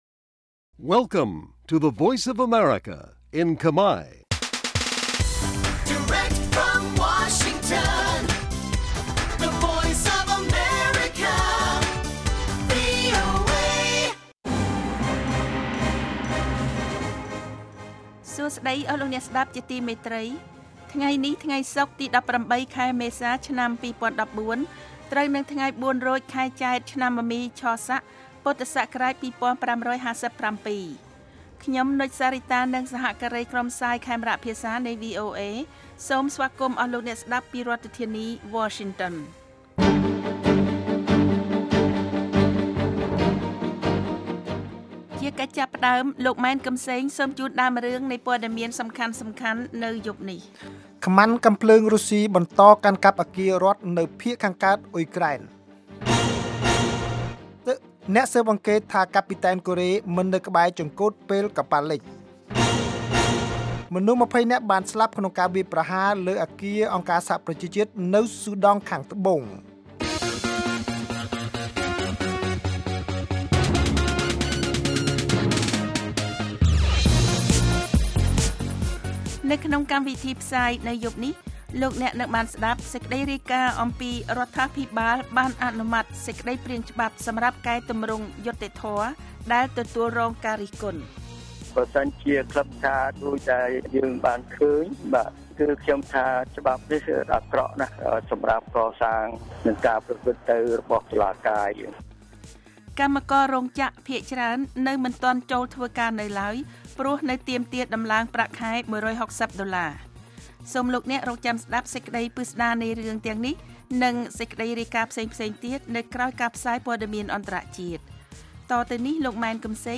នេះជាកម្មវិធីផ្សាយប្រចាំថ្ងៃតាមវិទ្យុ ជាភាសាខ្មែរ រយៈពេល ៦០ នាទី ដែលផ្តល់ព័ត៌មានអំពីប្រទេសកម្ពុជានិងពិភពលោក ក៏ដូចជាព័ត៌មានពិពណ៌នា ព័ត៌មានអត្ថាធិប្បាយ កម្មវិធីតន្ត្រី កម្មវិធីសំណួរនិងចម្លើយ កម្មវិធីហៅចូលតាមទូរស័ព្ទ និង បទវិចារណកថា ជូនដល់អ្នកស្តាប់ភាសាខ្មែរនៅទូទាំងប្រទេសកម្ពុជា។ កាលវិភាគ៖ ប្រចាំថ្ងៃ ម៉ោងផ្សាយនៅកម្ពុជា៖ ៨:៣០ យប់ ម៉ោងសកល៖ ១៣:០០ រយៈពេល៖ ៦០នាទី ស្តាប់៖ សំឡេងជា MP3